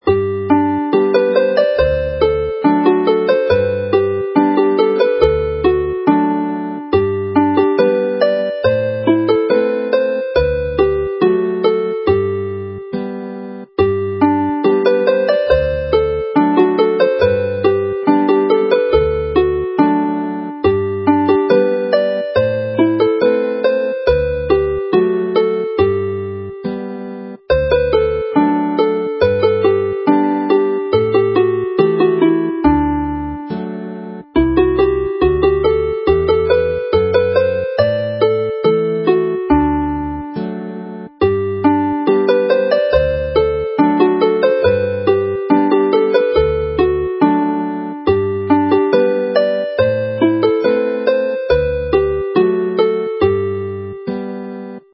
Play the melody slowly